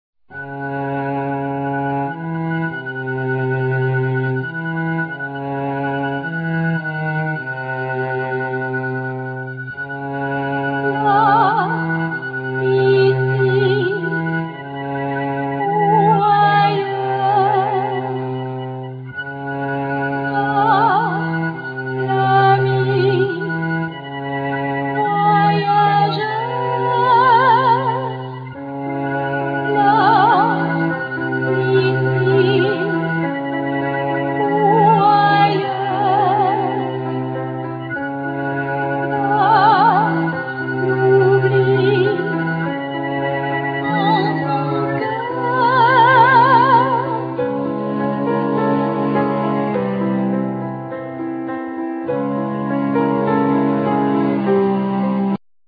Voices
Viola
Cello
Piano